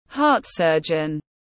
Bác sĩ phẫu thuật tim tiếng anh gọi là heart surgeon, phiên âm tiếng anh đọc là /hɑːt ˈsɜr·dʒən/.
Heart surgeon /hɑːt ˈsɜr·dʒən/